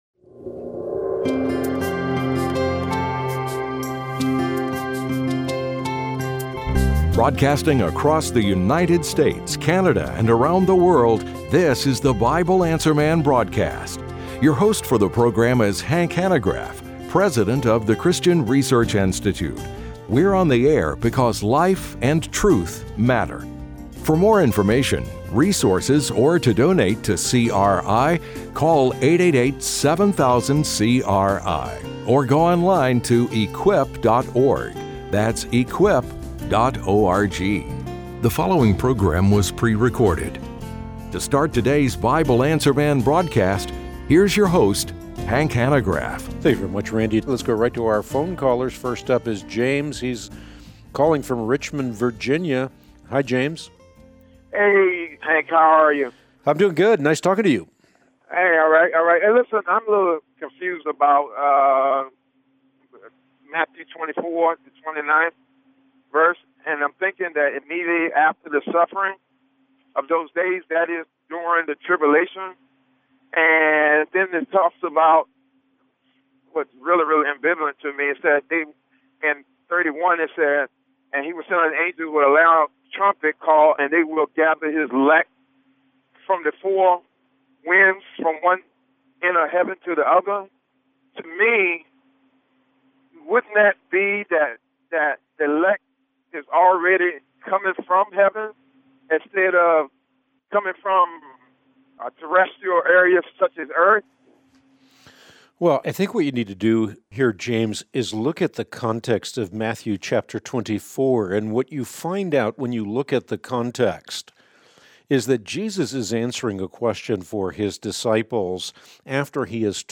On today’s Bible Answer Man broadcast (04/30/25), Hank answers the following questions: